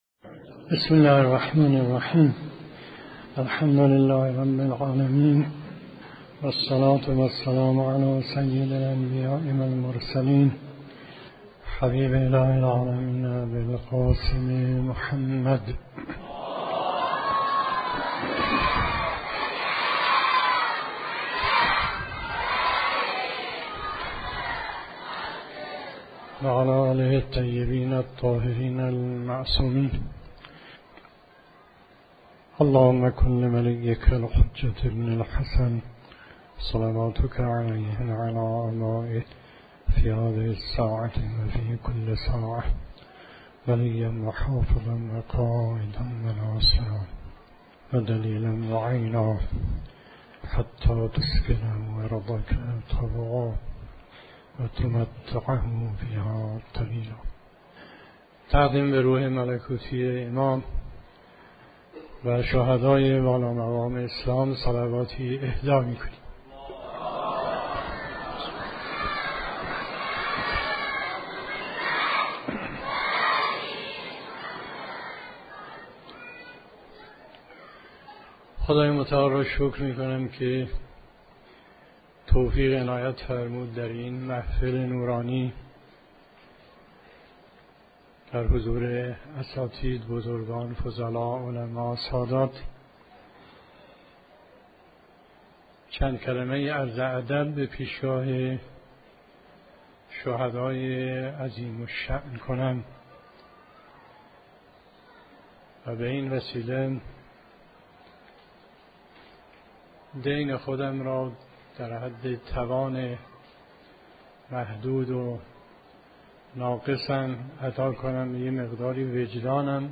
سخنرانی آیت الله مصباح یزدی درباره شهیدان؛ افتخار بشریت